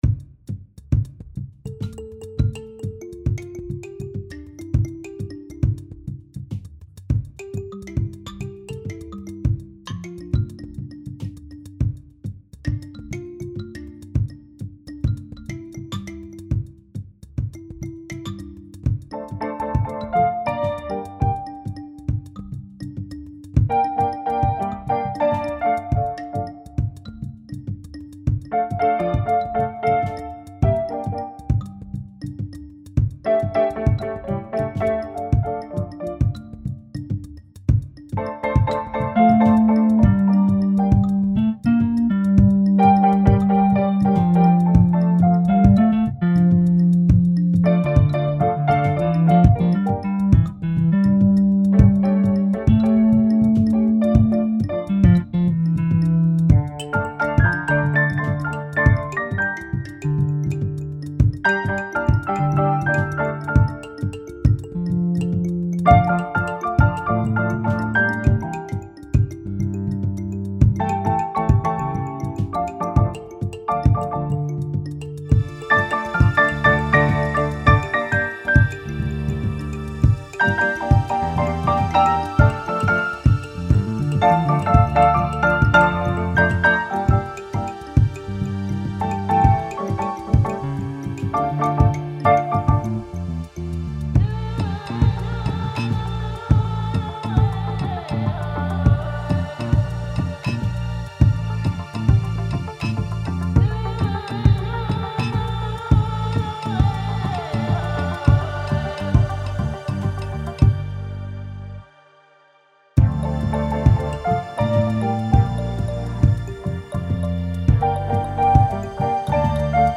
миниатюра в World music